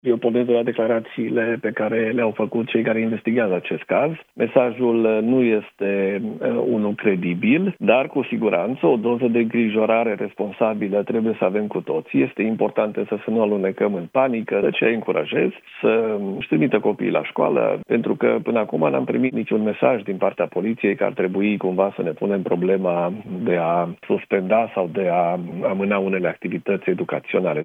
Activitățile educaționale în școlile care au primit mesajul de amenințare nu vor fi suspendate, spune la Europa FM ministrul Educației, Daniel David.